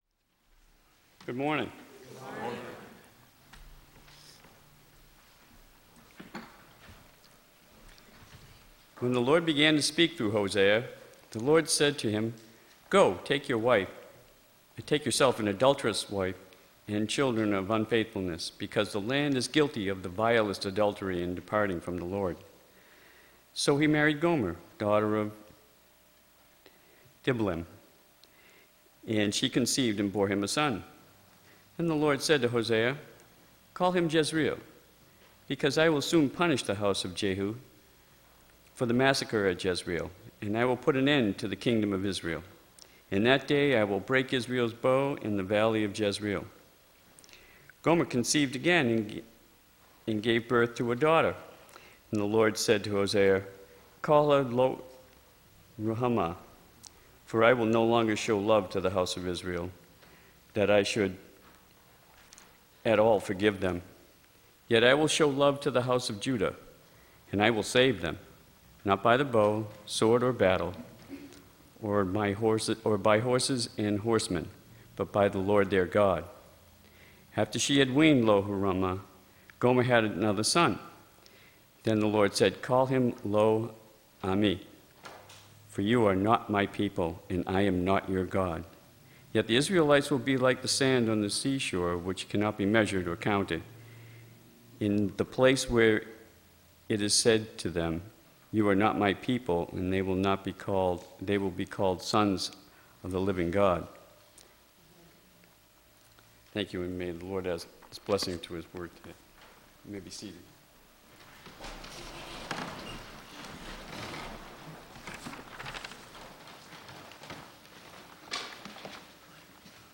Audio Recording of June 7th Worship Service – Now Available
The audio recording of our latest Worship Service is now available.